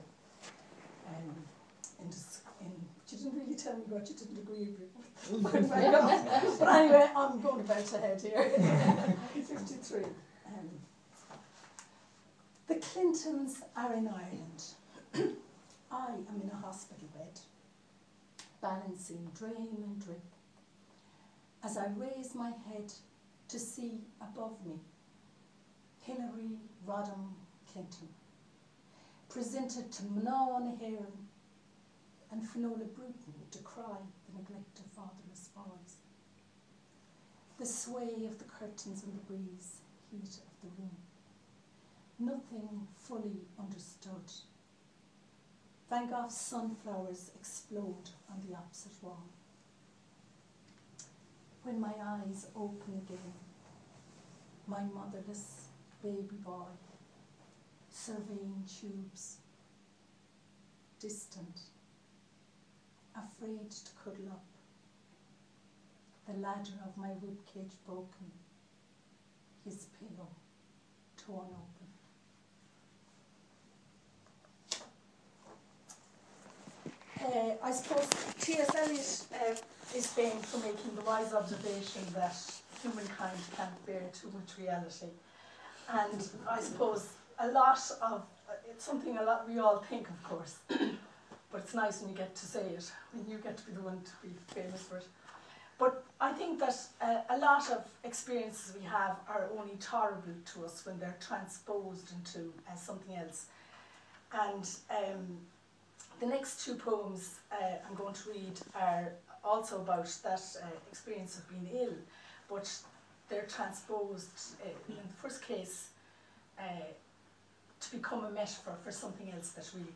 reading at the launch of her book